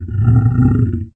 lion.ogg